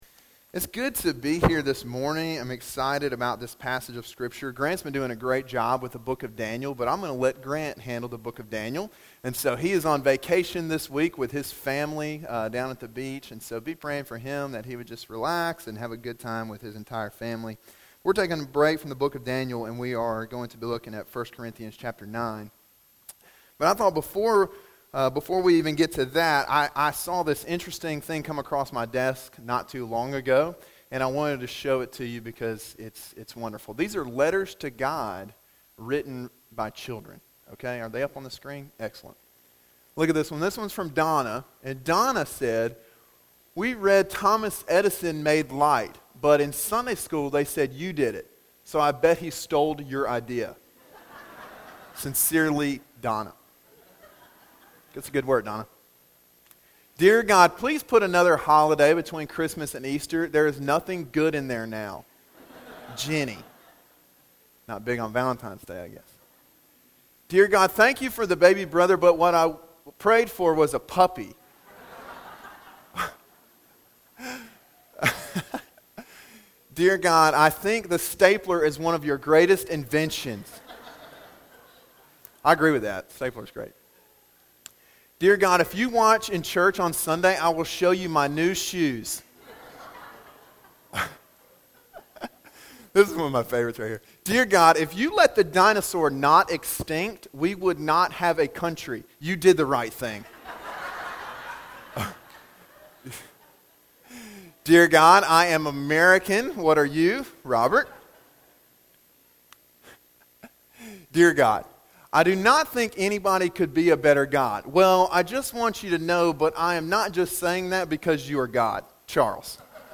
Sermon Audio: “Whatever It Takes” (1 Corinthians 9:19-27)